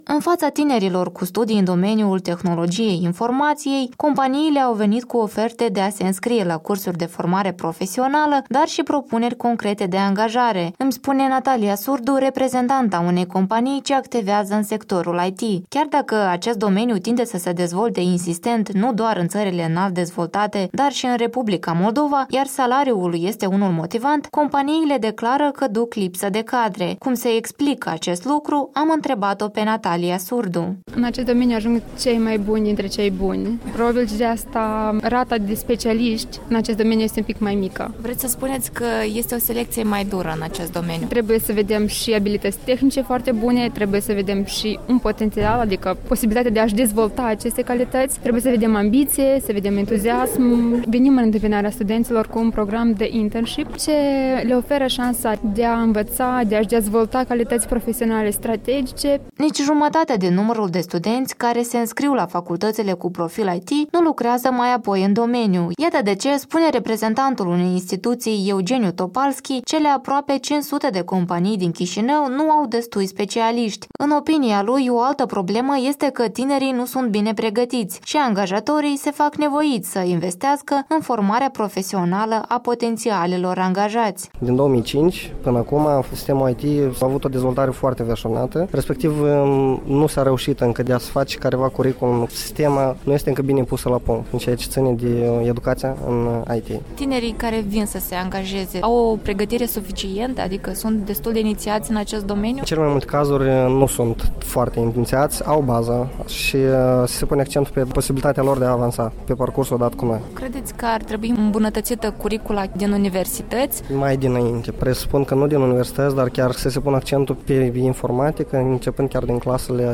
Un reportaj de la Târgul locurilor de muncă în domeniul IT